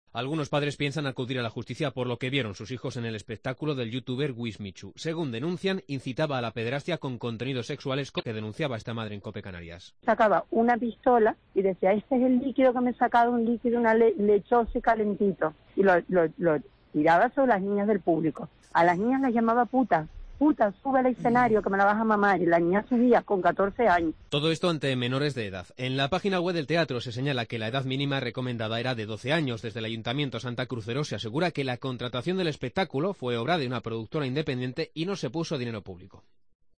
Incluye testimonios de padres a la emisora de COPE